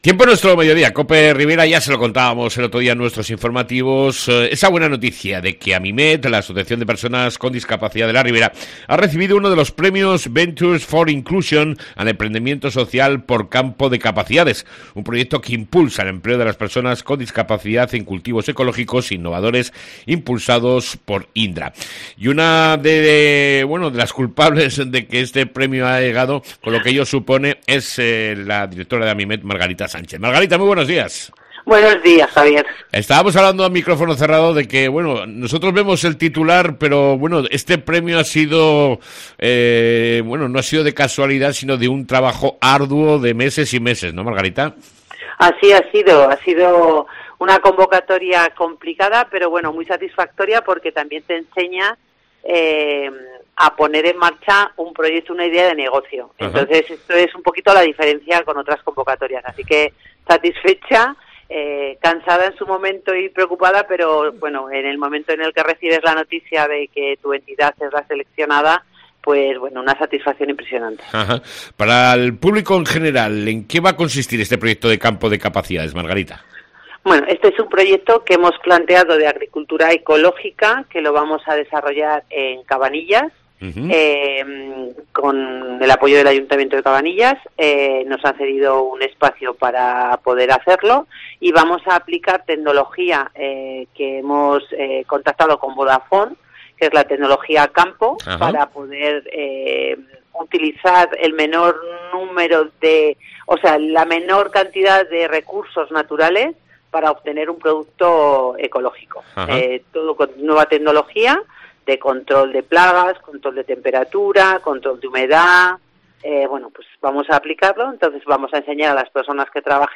Audios Tudela